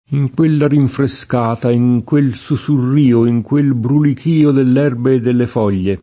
ij kU%lla rinfreSk#ta, ij kU%l SuSurr&o, ij kU%l brulik&o dell $rbe e ddelle f0l’l’e] (Manzoni); Così parlò tra il sussurrìo dell’onde [koS& pparl0 ttra il SuSSurr&o dell 1nde] (Pascoli)